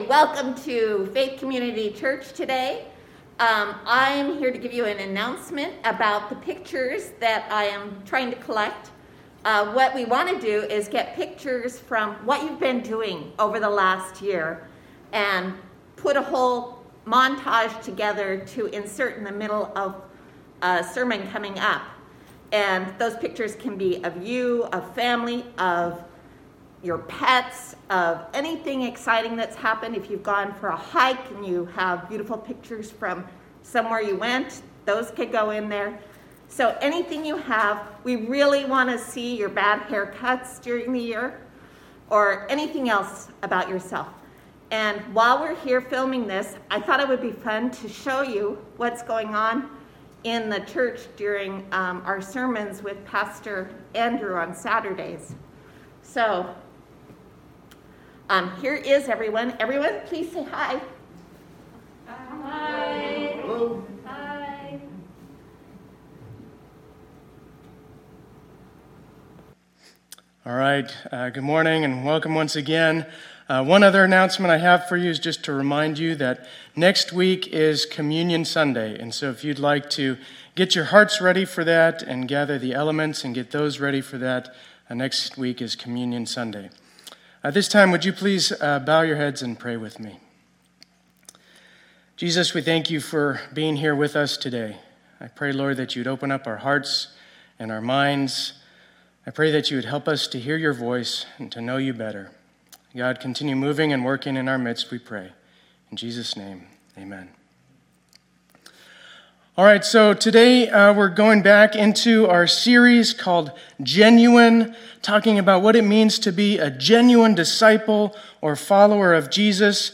2021-01-31 Sunday Service